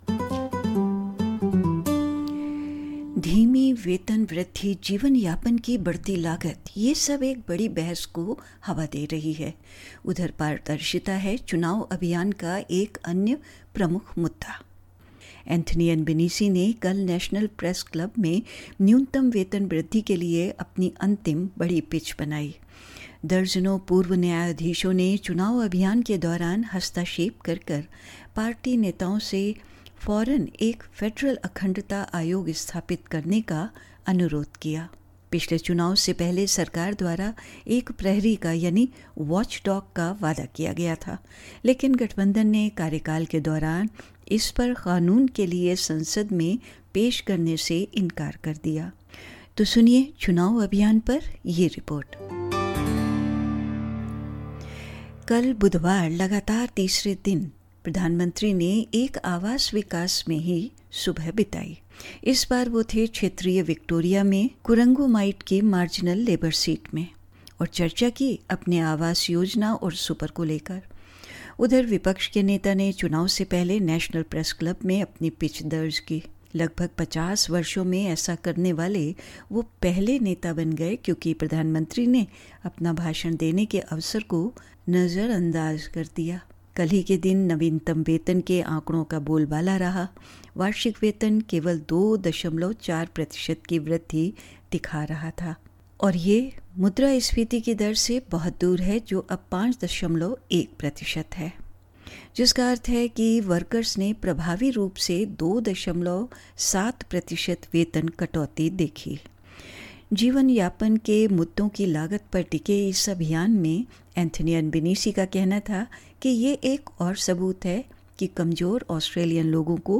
दर्जनों पूर्व न्यायाधीशों ने चुनाव अभियान के दौरान हस्तक्षेप करके पार्टी नेताओं से तत्काल एक फेडरल अखंडता आयोग स्थापित करने का अनुरोध किया। सुनिये चुनाव अभियान पर यह रिपोर्ट